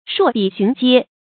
搠笔巡街 shuò bǐ xún jiē
搠笔巡街发音